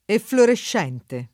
[ efflorešš $ nte ]